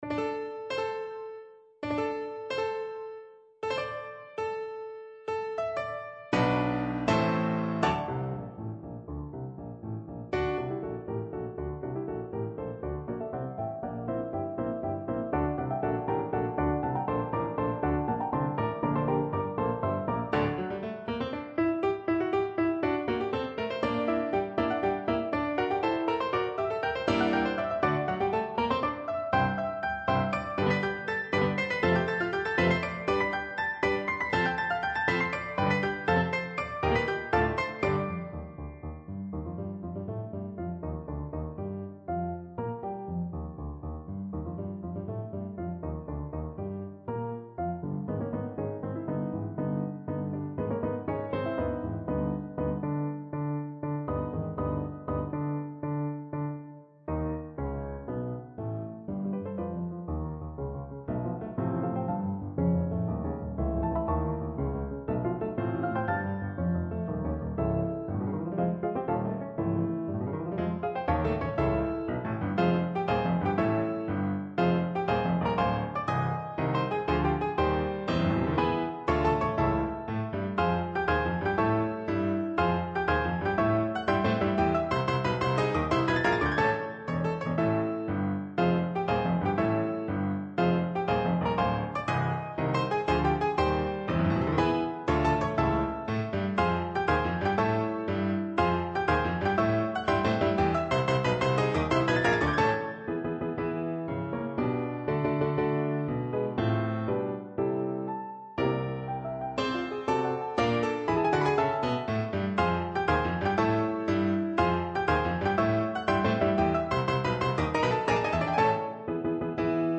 for two pianos
Piano - 2 players on 2 pianos